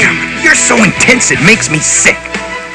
YAY! we all love da sound clips! i gots some of da clips of Duo from da dubbed version of GW. subbed would b better but Its DUO so i dont care! click on the clip u wanna hear and enjoy!